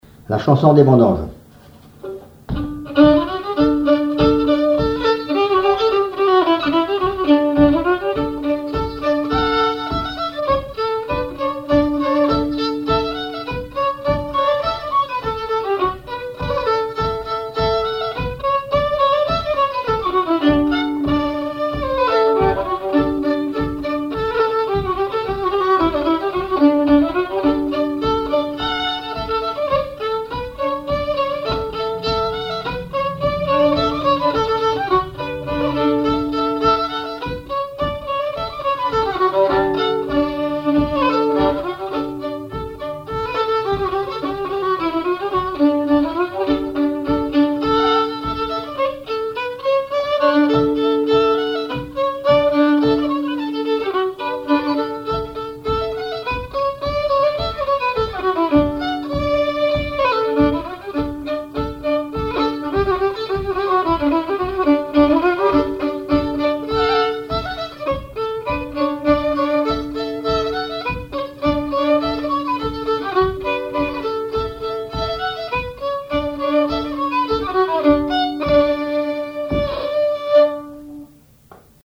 violoneux, violon
danse : scottich trois pas
instrumentaux au violon mélange de traditionnel et de variété
Pièce musicale inédite